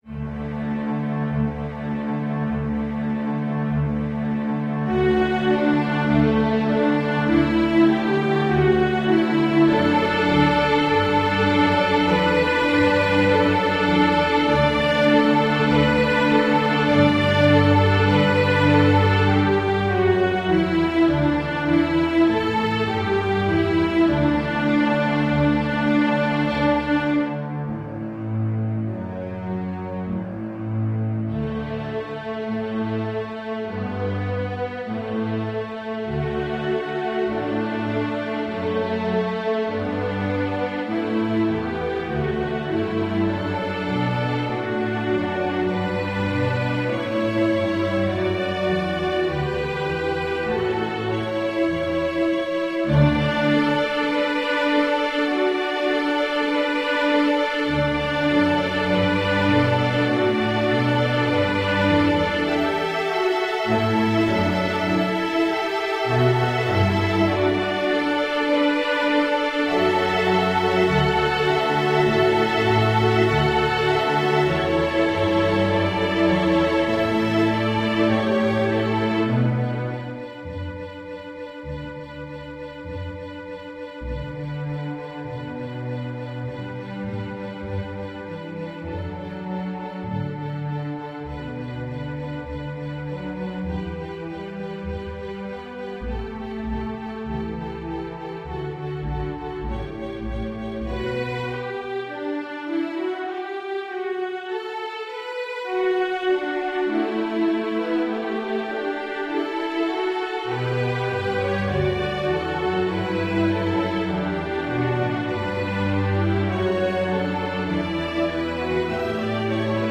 Genre: String Orchestra
Violin I
Violin II
Viola
Cello
Contrabass